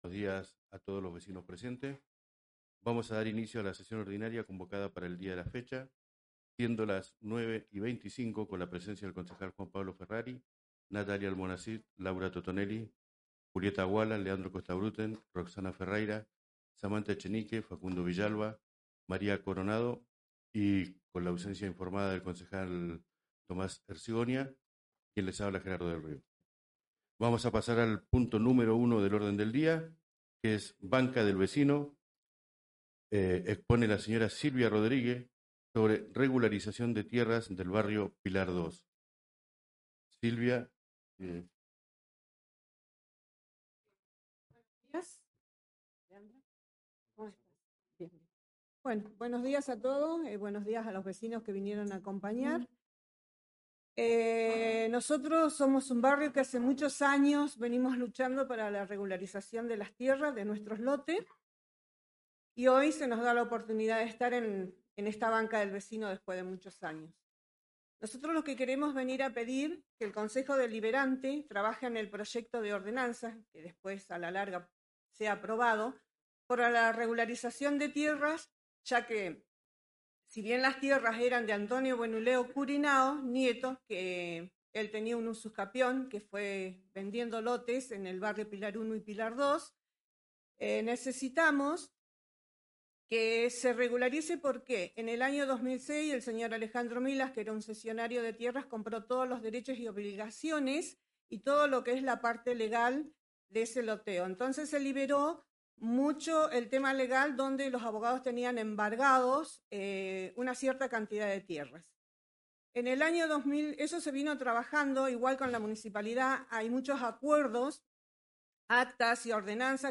Carácter de la Sesión: Ordinaria. Lugar de realización : Sala Francisco P. Moreno, Concejo Municipal, San Carlos de Bariloche, Provincia de Río Negro, República Argentina.